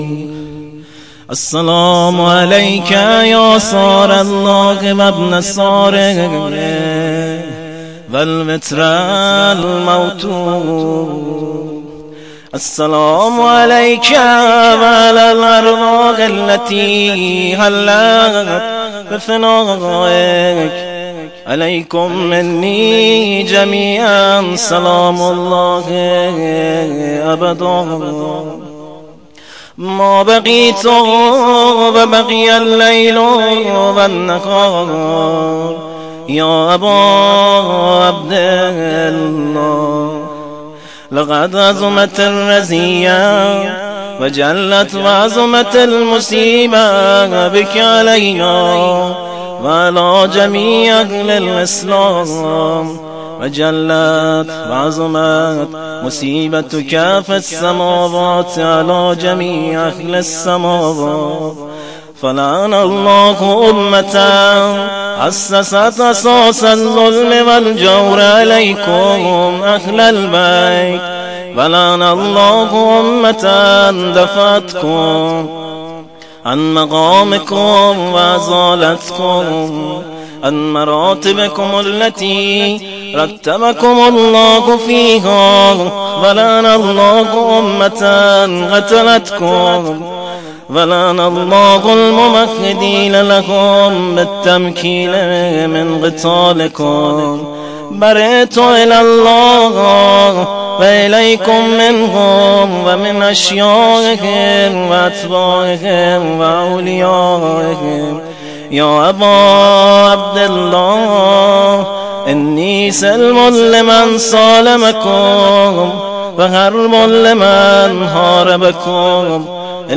خیمه گاه - هیئت مهدیه احمد آباد - شب 11 محرم زیارت عاشورا